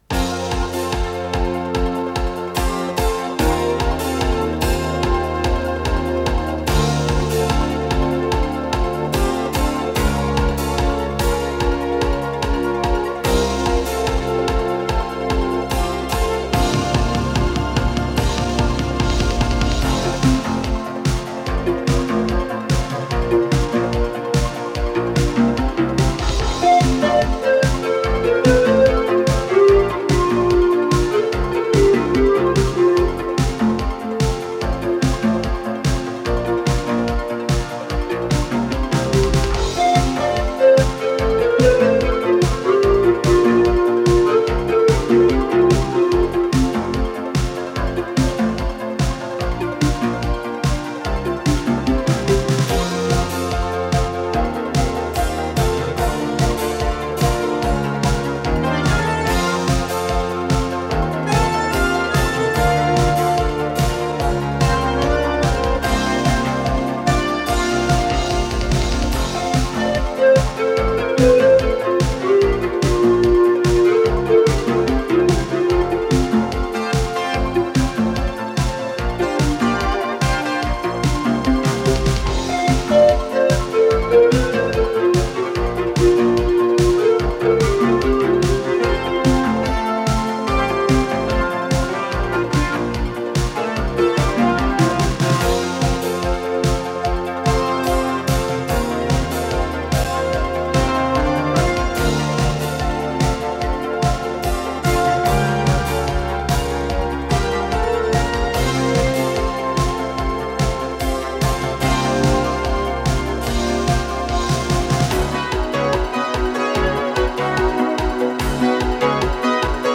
с профессиональной магнитной ленты
Скорость ленты38 см/с
ВариантДубль моно